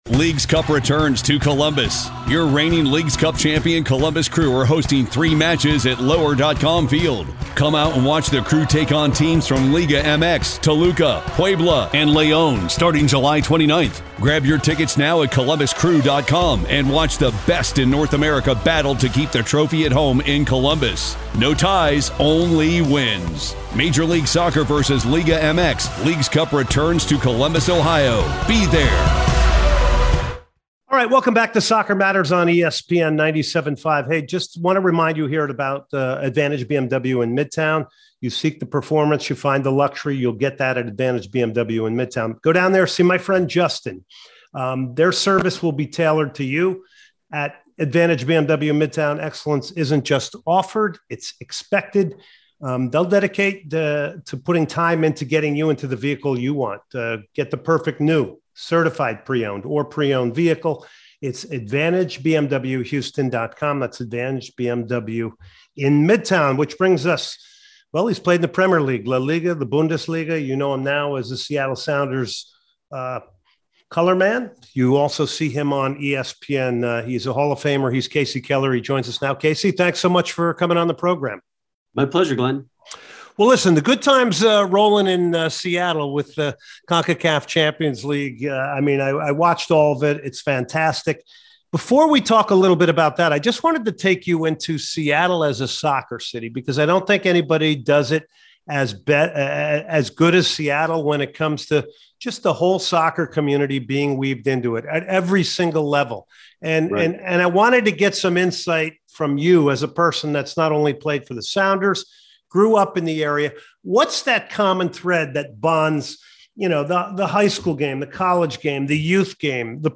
05/17/2022 Former USMNT GK Kasey Keller joins Soccer Matters